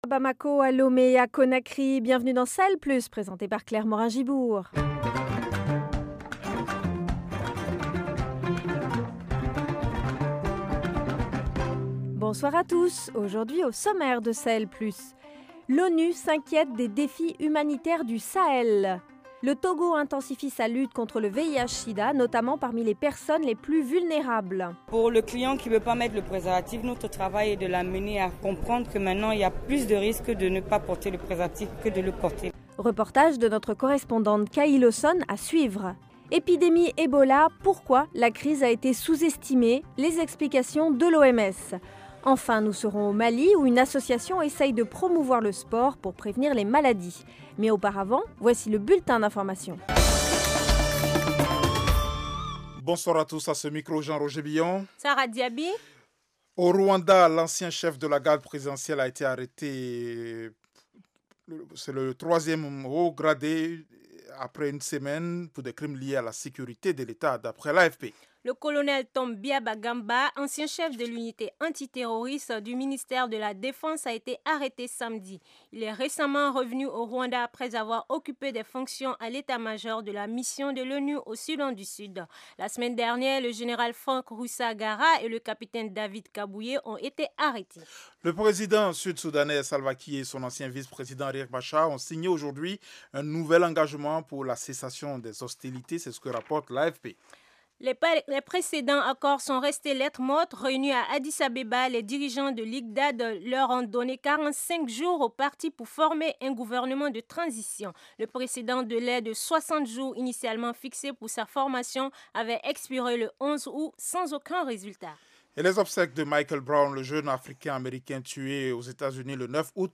Au programme : l’ONU s’inquiète des défis humanitaires du Sahel. Reportage : le Togo intensifie sa lutte contre le VIH/Sida pour les groupes à risque. Ebola : pourquoi la crise a été sous-estimée ?